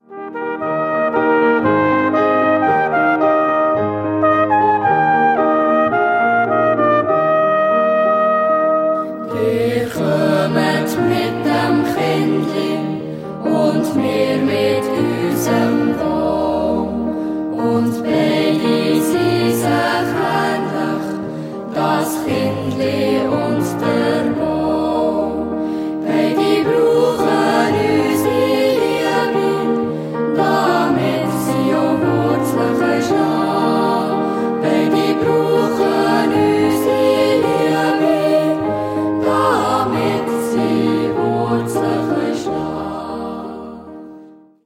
Chor, Flügelhorn, Klavier